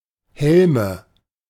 The Helme (German pronunciation: [ˈhɛlmə]